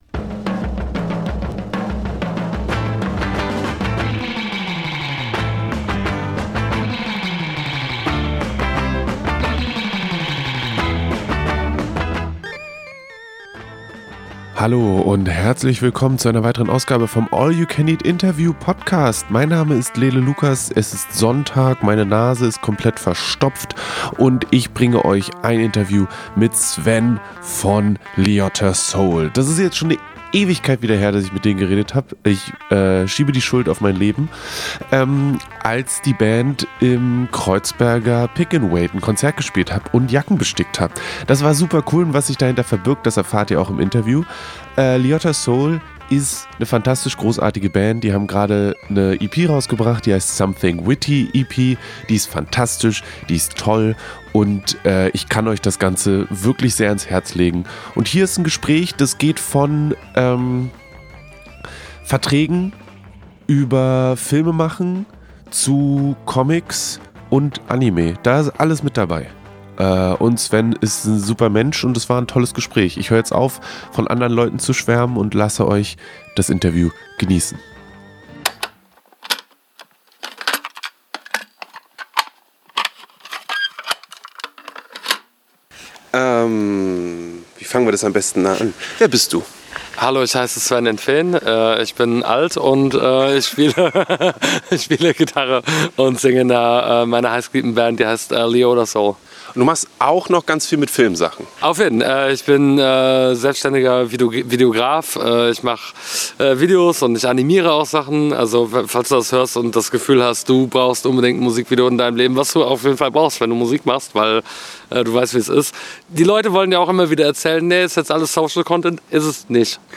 interview-mit-liotta-seoul-2024.mp3